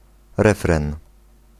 Ääntäminen
Synonyymit ritournelle Ääntäminen France: IPA: [ʁə.fʁɛ̃] Haettu sana löytyi näillä lähdekielillä: ranska Käännös Konteksti Ääninäyte Substantiivit 1. refren {m} musiikki Suku: m .